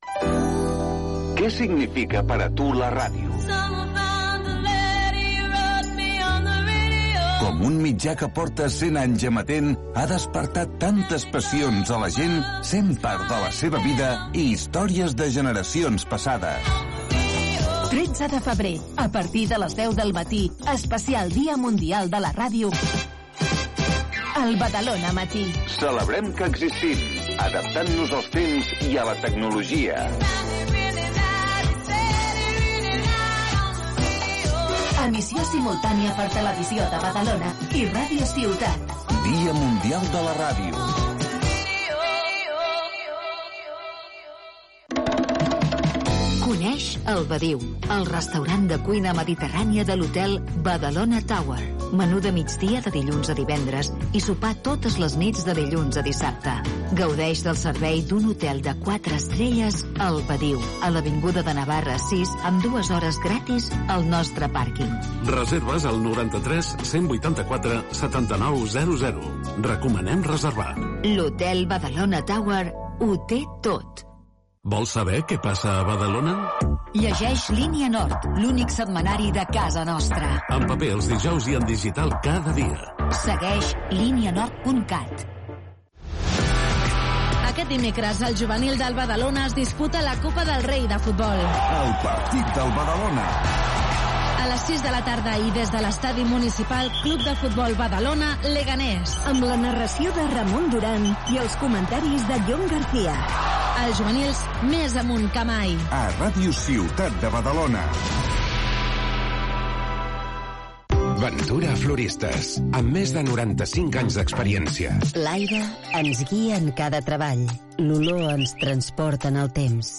Identificació del programa, publicitat, promoció "El partit del Badalona", indicatiu de l'emissora.
Paraules de Luis del Olmo dedicades a la ràdio. Entrevista a l'alcalde de Tiana Isaac Salvatierra, periodista, que, de jove, va crear una ràdio al seu carrer i poc després Ràdio Tiana
Info-entreteniment